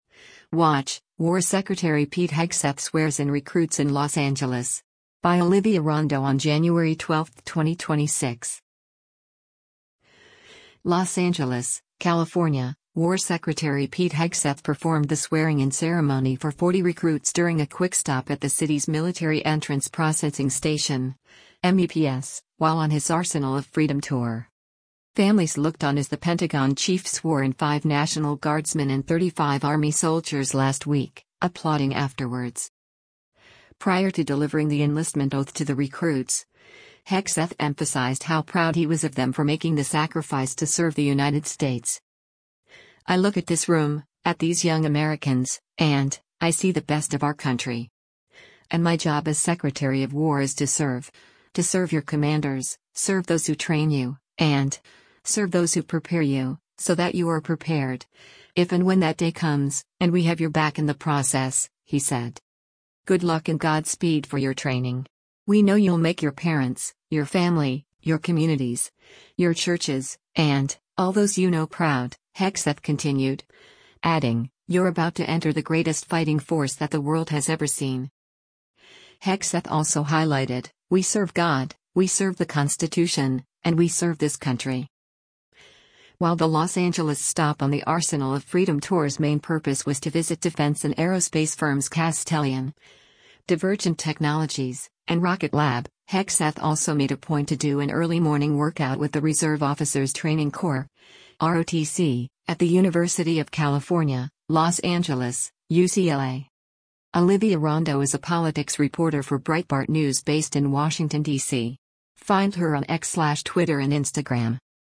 WATCH: War Secretary Pete Hegseth Swears in Recruits in Los Angeles
LOS ANGELES, California — War Secretary Pete Hegseth performed the swearing-in ceremony for 40 recruits during a quick stop at the city’s Military Entrance Processing Station (MEPS) while on his “Arsenal of Freedom” tour.
Families looked on as the Pentagon chief swore in five National Guardsmen and 35 Army soldiers last week, applauding afterwards: